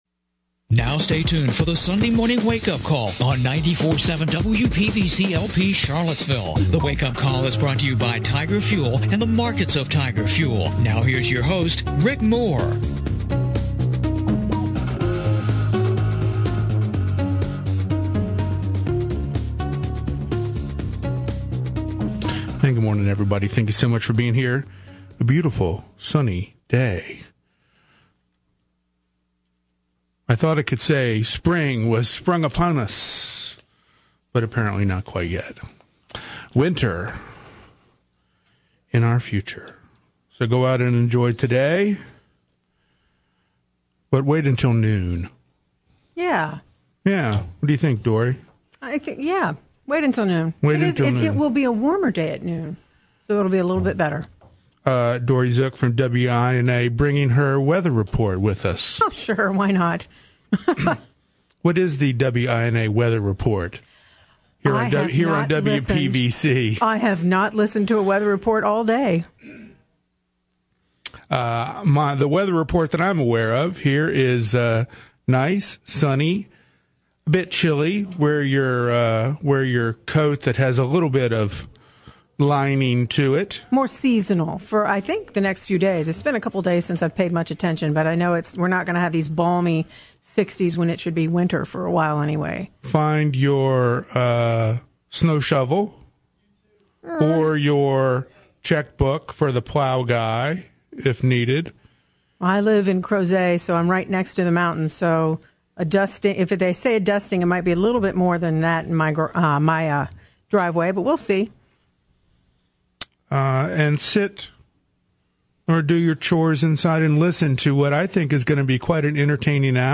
The Sunday Morning Wake-Up Call is heard on WPVC 94.7 Sunday mornings at 11:00 AM.